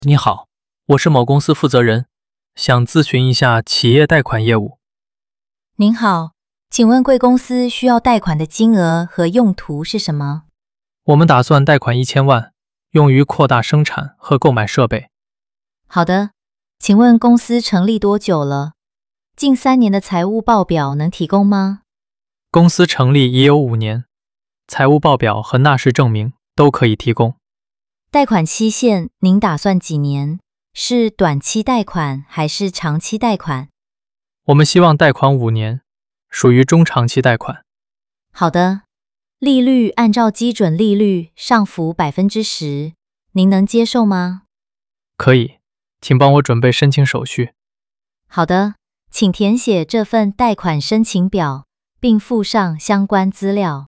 Hội thoại 4: Vay vốn – Tín dụng doanh nghiệp